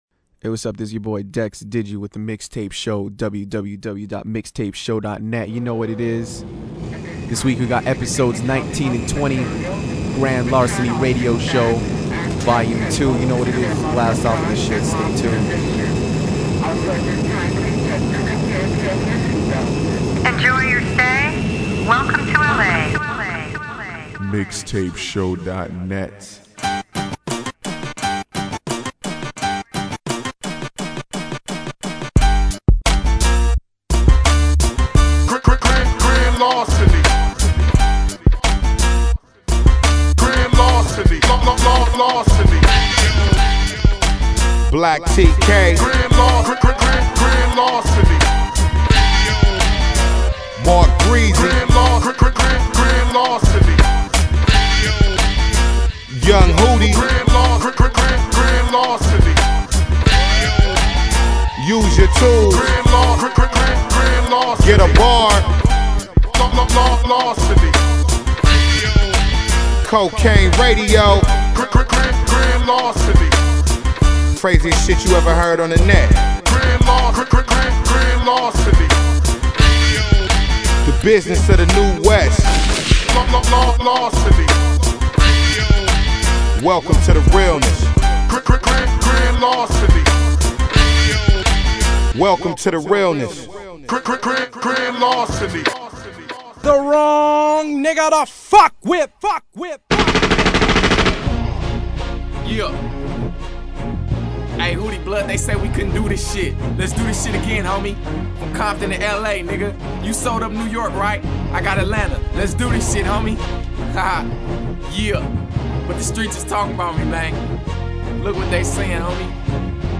right here on the most known unknown hip-hop podcast ever